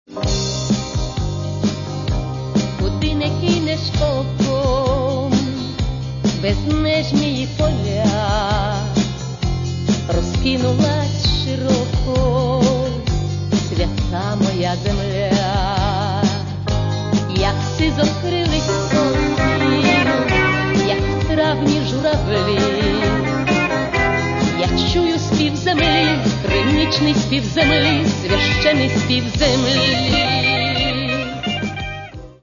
Здається, цим пісням дещо бракує тихого шурхотіння.